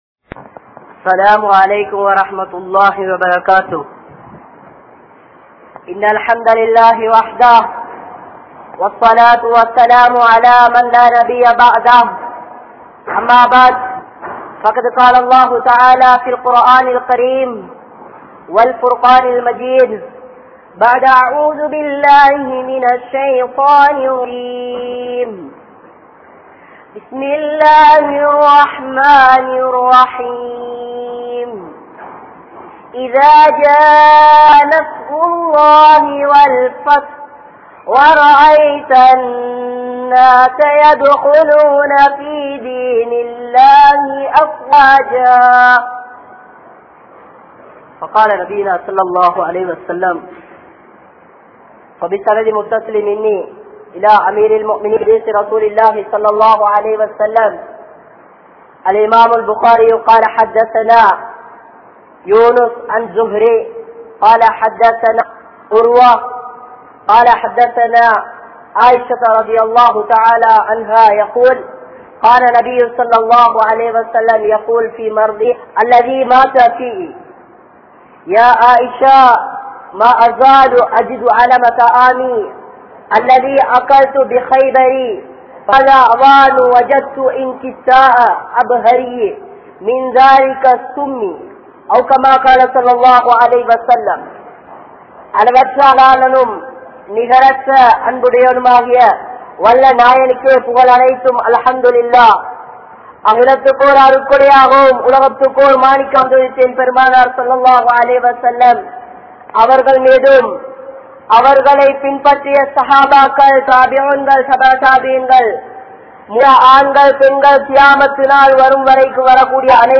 Iruthi Nabien Iruthi Nearam (இறுதி நபியின் இறுதி நேரம்) | Audio Bayans | All Ceylon Muslim Youth Community | Addalaichenai
Nawalapitiya, Balanthota, Badhuriya Jumua Masjidh